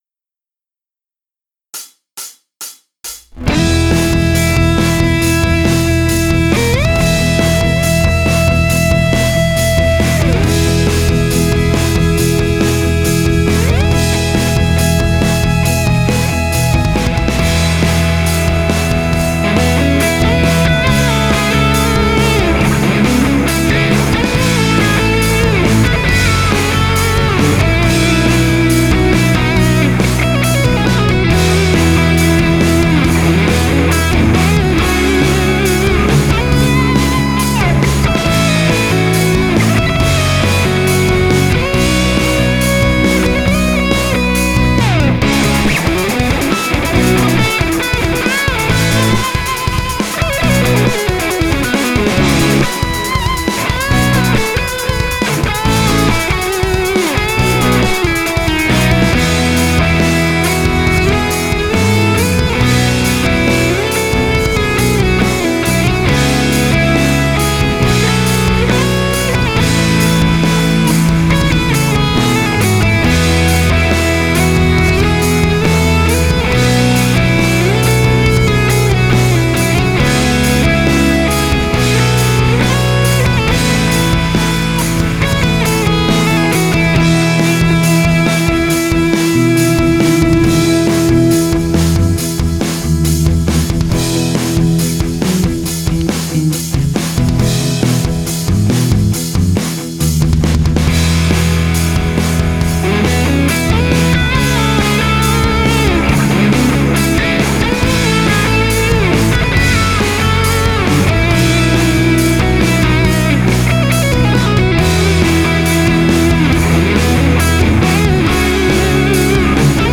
bateria